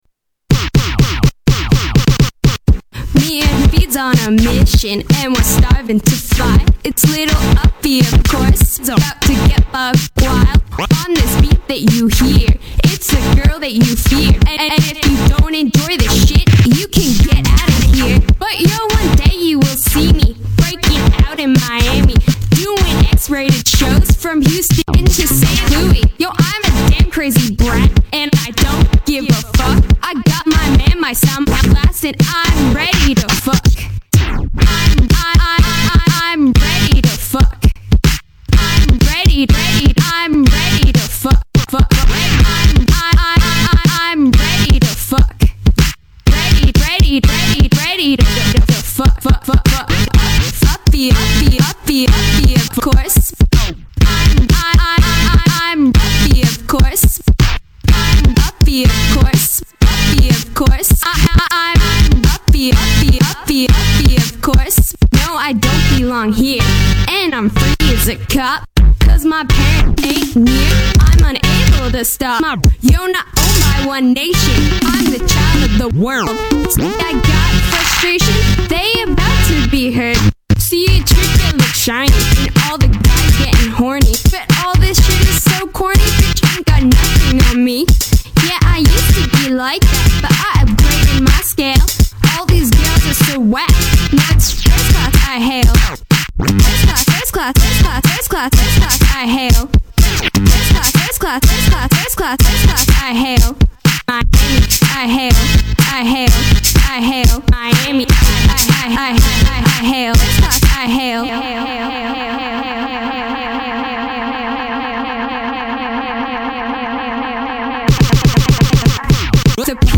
Electro Rap